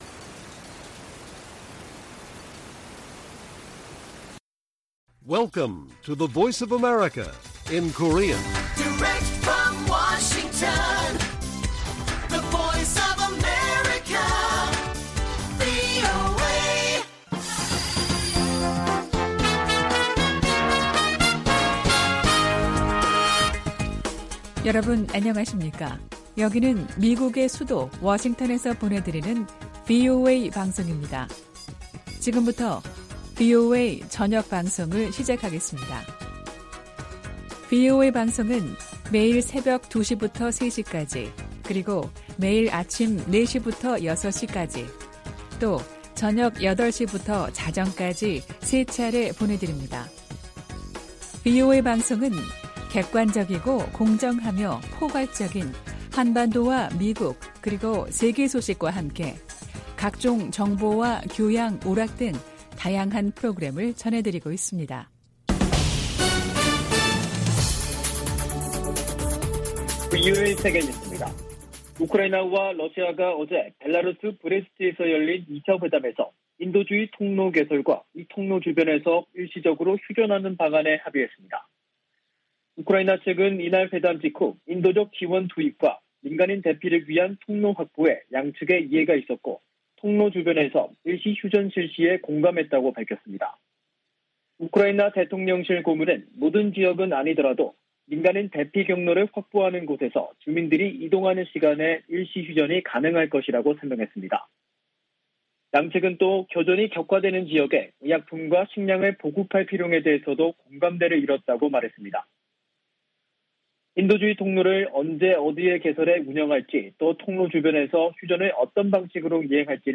VOA 한국어 간판 뉴스 프로그램 '뉴스 투데이', 2022년 3월 4일 1부 방송입니다. 미 국무부는 러시아가 한국의 제재 동참에 유감을 표명한 데 대해, 국제사회가 무의미한 전쟁을 방어하는데 단결하고 있다고 강조했습니다. 북한이 우크라이나 사태에 러시아를 적극 두둔한 것은 핵 보유국 지위 확보를 노린 것이라는 분석이 나오고 있습니다. 미 상원의원들이 북한 등을 암호화폐 악용 국가로 지목하고 대책 마련을 촉구했습니다.